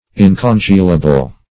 Search Result for " incongealable" : The Collaborative International Dictionary of English v.0.48: Incongealable \In`con*geal"a*ble\, a. [L. incongelabilis.